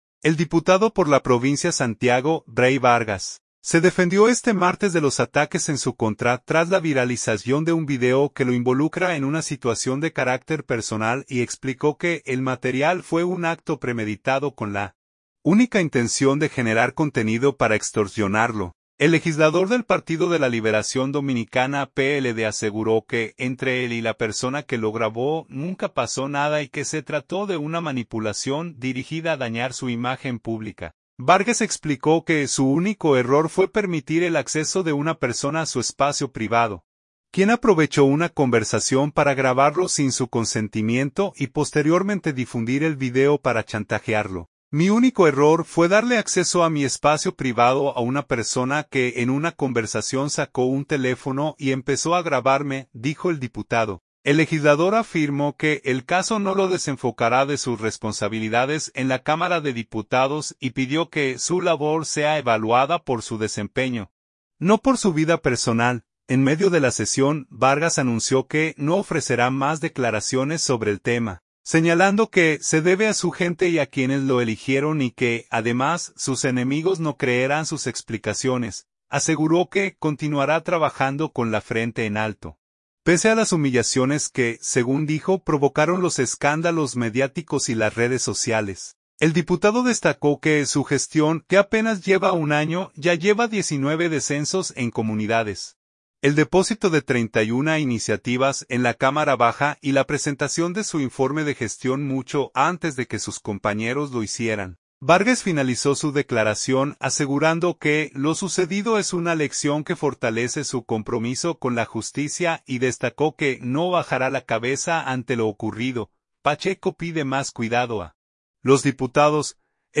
En medio de la sesión , Vargas anunció que no ofrecerá más declaraciones sobre el tema, señalando que se debe a "su gente y a quienes lo eligieron" y que, además, sus enemigos no creerán sus explicaciones.